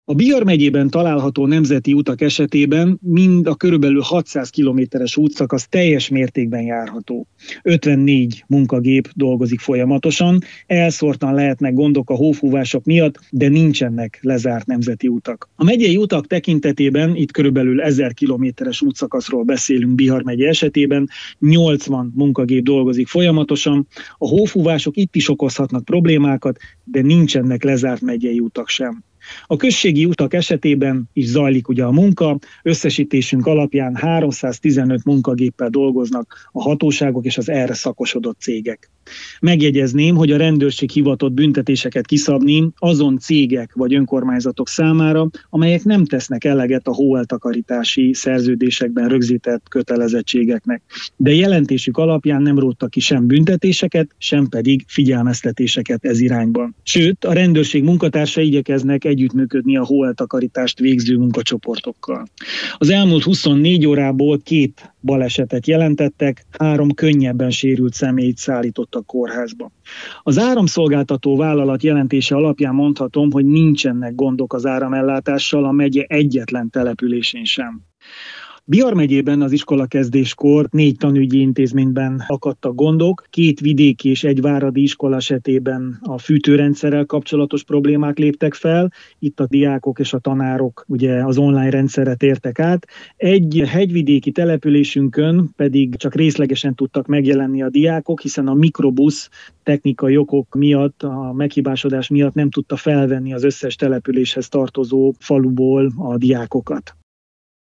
Bihar megyében 3 hegyi útszakasz kivételével nincsenek útlezárások – nyilatkozta rádiónknak Botházy Nándor Bihar megyei alprefektus.